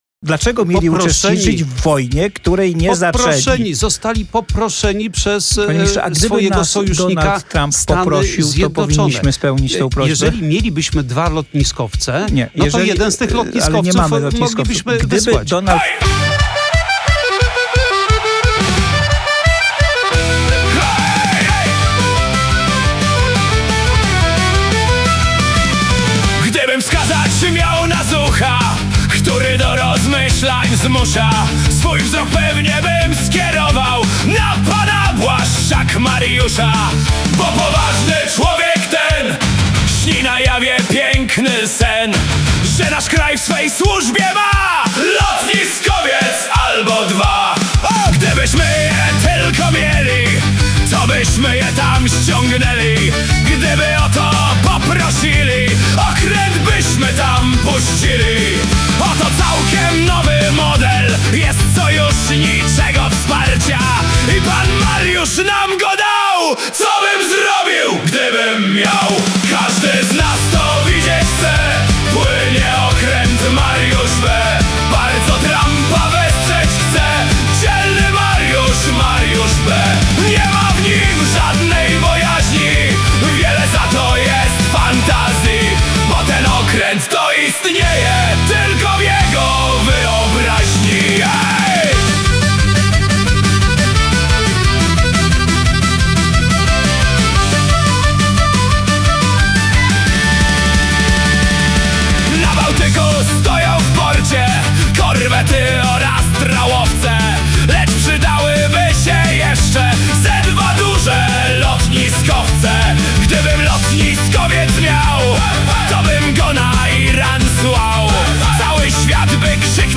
produkcja AI.